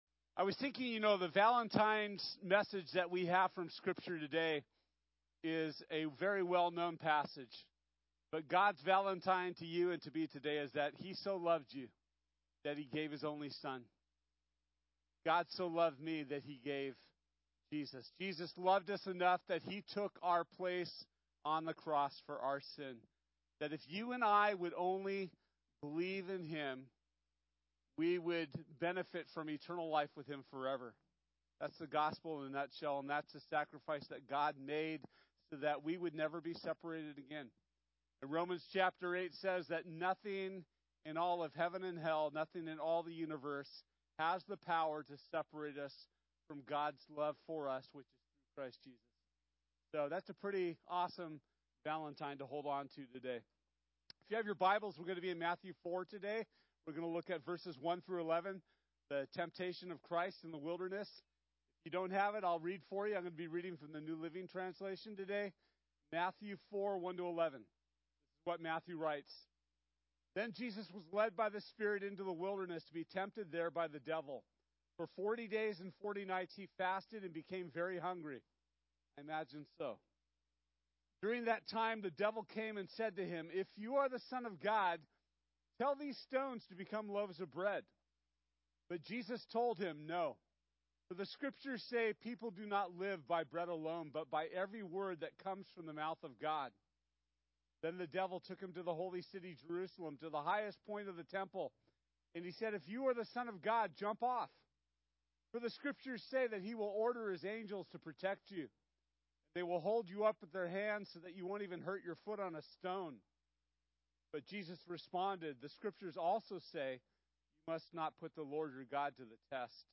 Matthew 4:1-11 Service Type: Sunday This week we’re looking at Jesus’ testing in the wilderness.